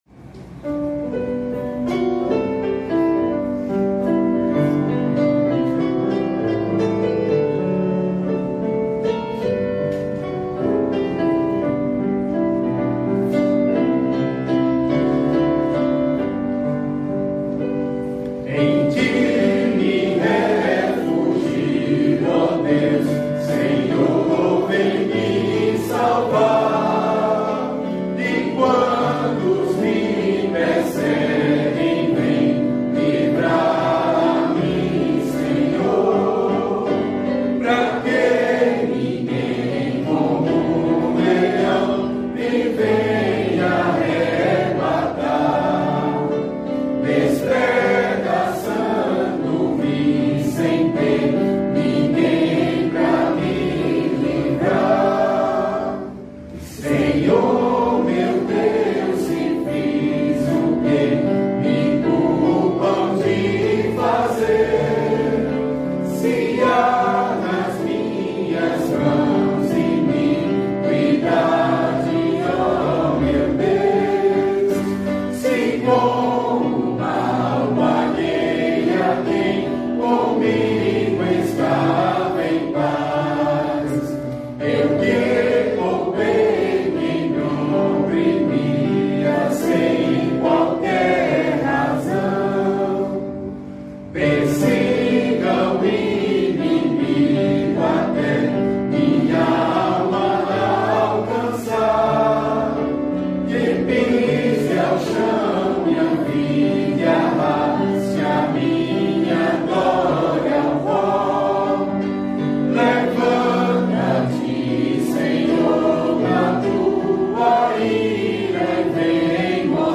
salmo_7B_cantado.mp3